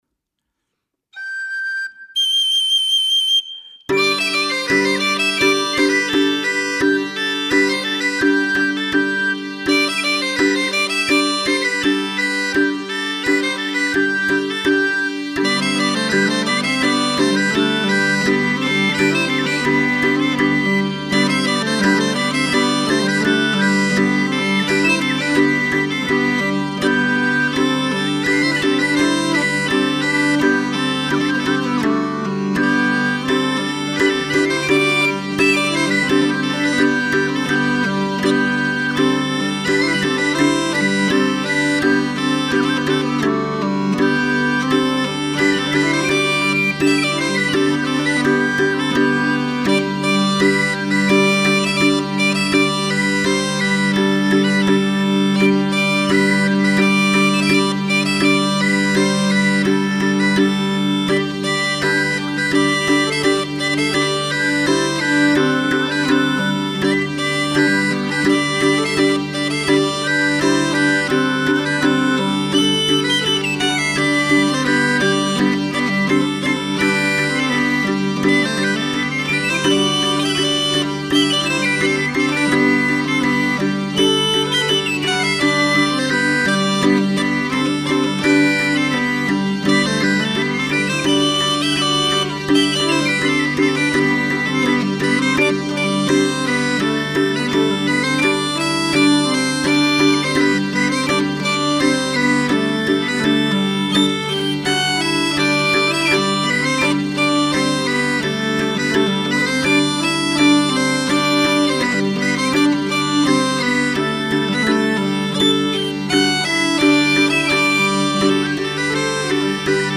Oiartzungo Lezoti estudioan grabatuta.